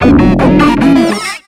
Cri de Grodrive dans Pokémon X et Y.